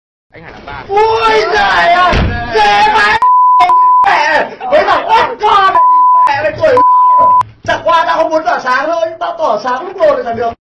Thể loại: Câu nói Viral Việt Nam
Hiệu ứng âm thanh meme Ui dời ơi dễ vãi l*z sound effect thể hiện cảm xúc sự tức giận, bức xúc hài hước